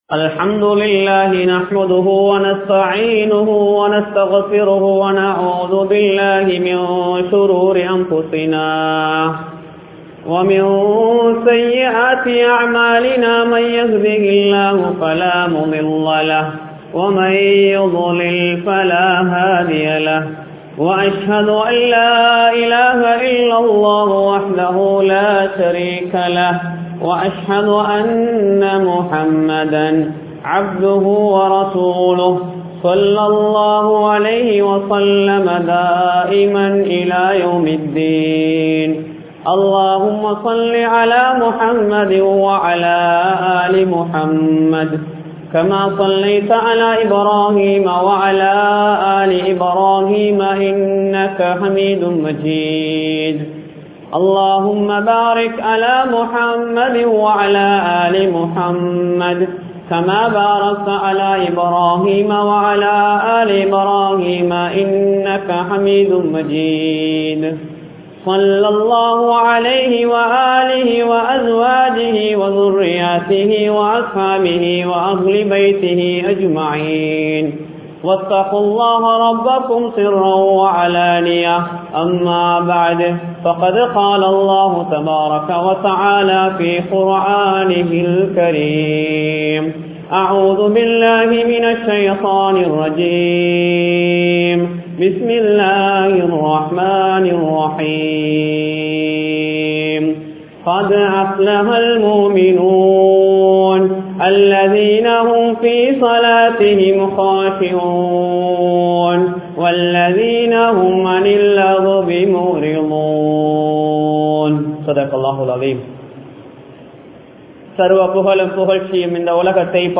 Nalladiyaarhalin Adaiyaalangal (நல்லடியார்களின் அடையாளங்கள்) | Audio Bayans | All Ceylon Muslim Youth Community | Addalaichenai
Dehiwela, Muhideen (Markaz) Jumua Masjith